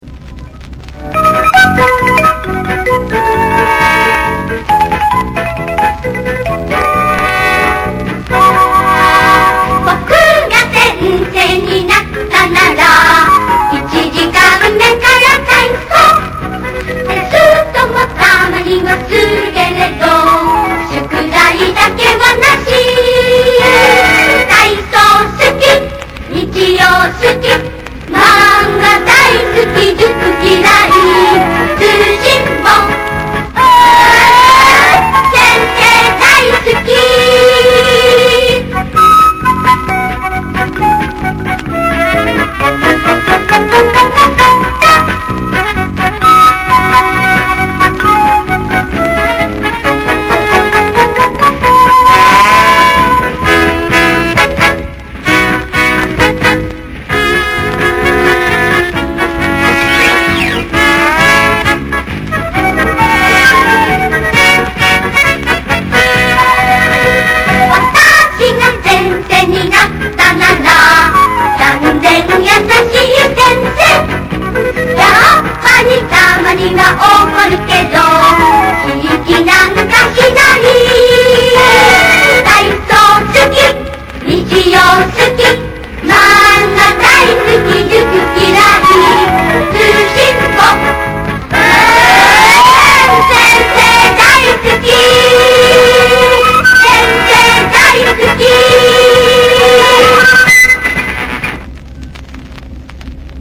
一首日本电影的插曲
我这首是在老唱片上转过来，音质很差，哪位老师有音质好点的，麻烦分享下呗，先谢了哈！